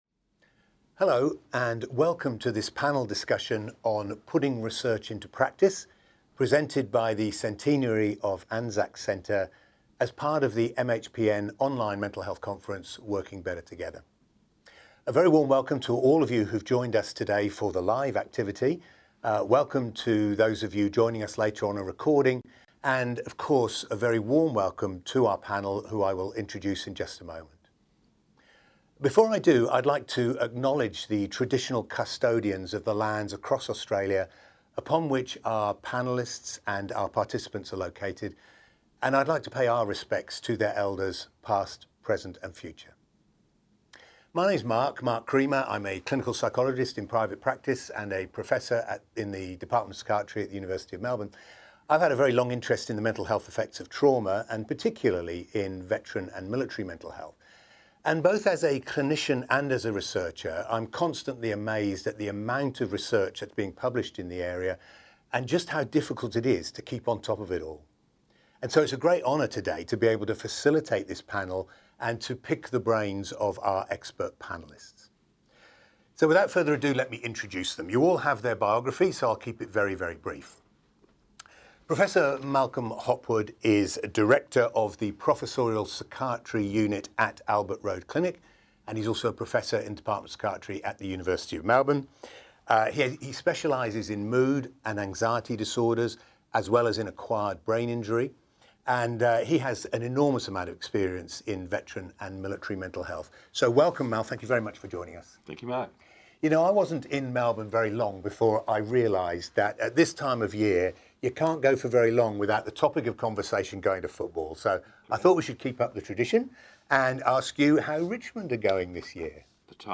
Join this discussion between interdisciplinary experts about the practicalities of translating research into clinical practice. Presented by the Centenary of Anzac Centre, this webinar is part of the Working Better Together Online Conference 2019 hosted by the Mental Health Professionals' Network (MHPN)